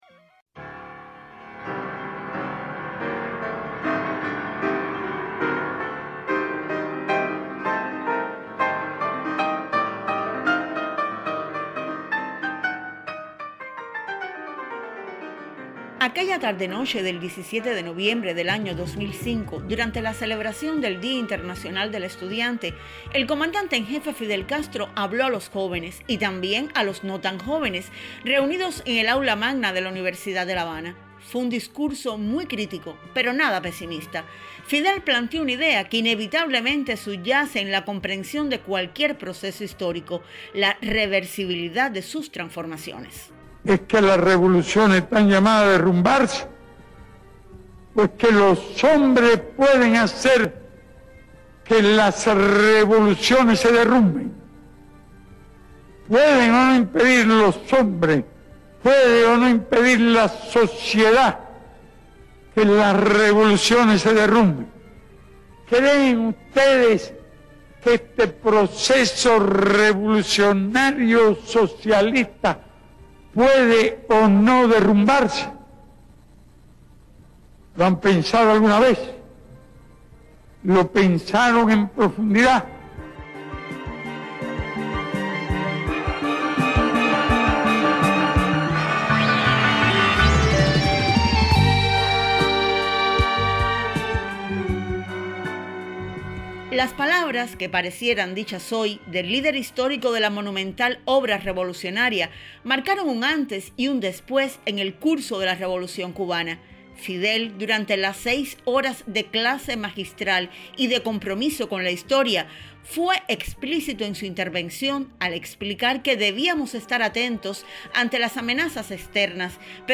Aquel 17 de noviembre de 2005, en el Aula Magna de la Universidad de La Habana, el Comandante en Jefe Fidel Castro Ruz lanzó una reflexión que aún desafía a todos los seres humanos de bien, y en especial a los cubanos.